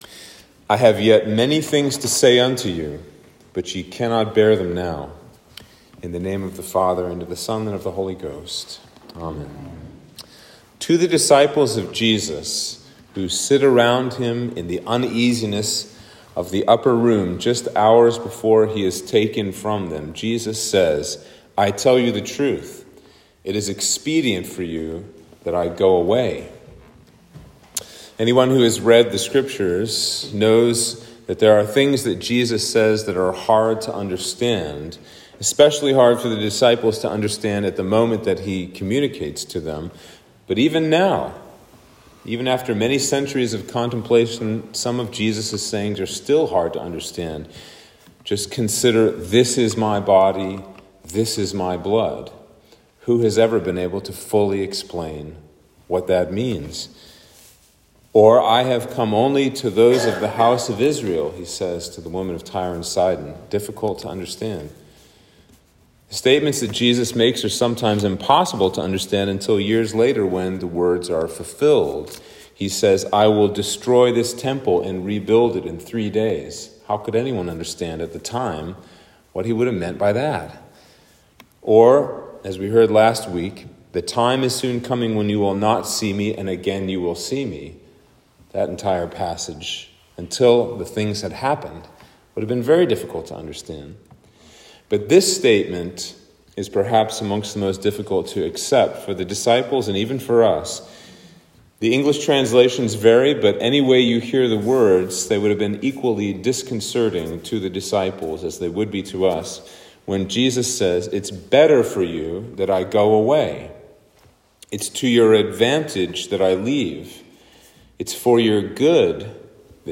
Sermon for Easter 4